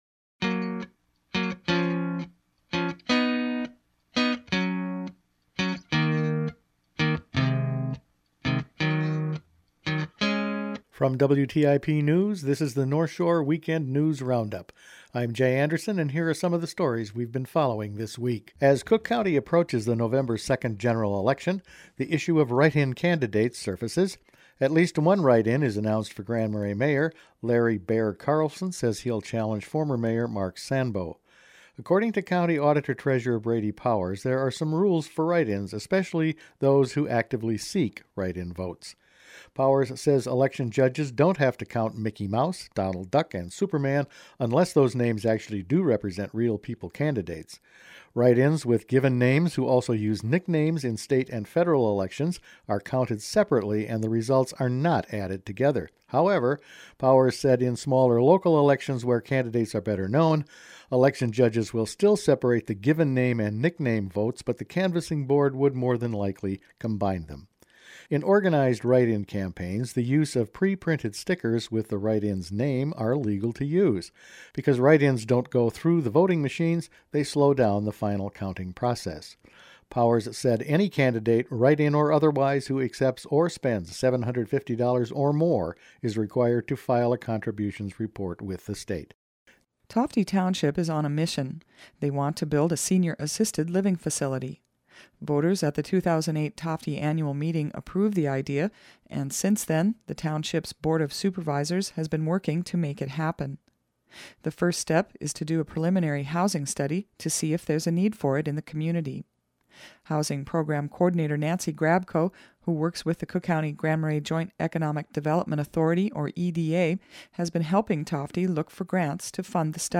Each weekend the WTIP News Department assembles the week’s important news stories to play here on North Shore Weekend. Among other things, this week we’ve been covering Tofte assisted living, write-in candidates and Great Lakes clean-up.